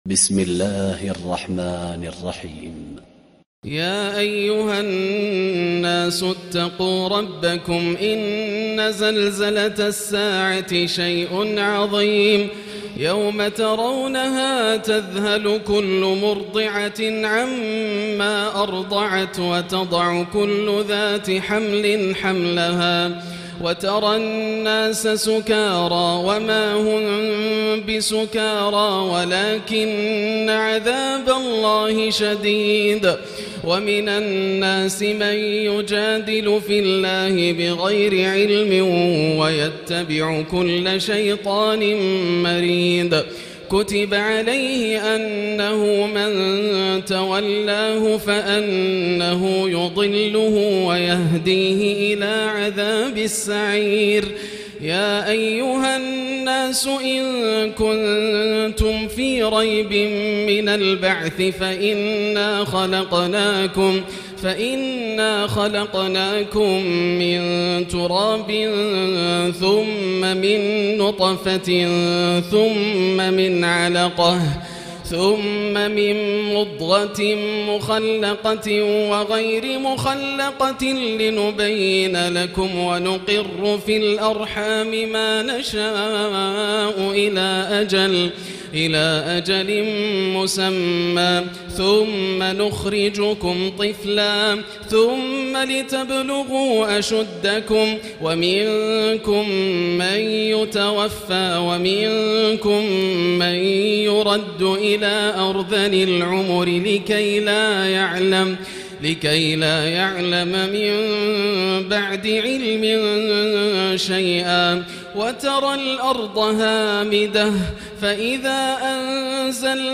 الليلة السادسة عشر سورة الحج كاملة > الليالي الكاملة > رمضان 1439هـ > التراويح - تلاوات ياسر الدوسري